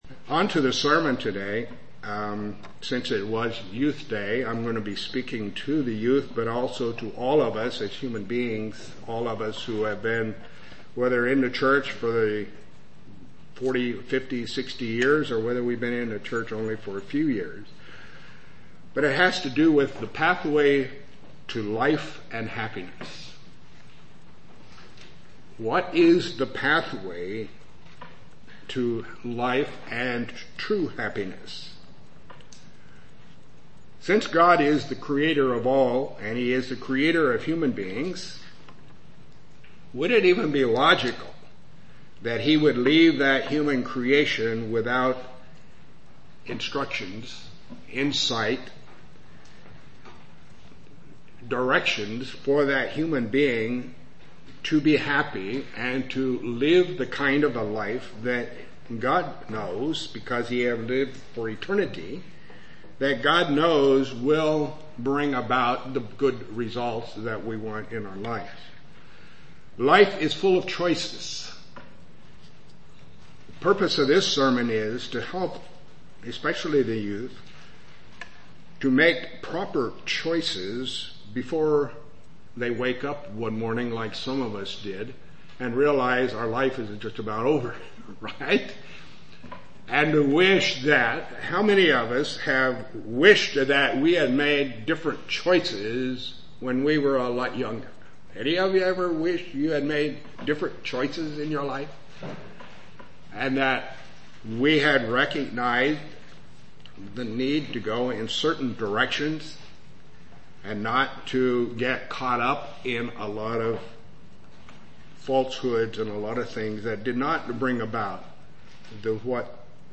Given in Lewistown, PA
UCG Sermon Studying the bible?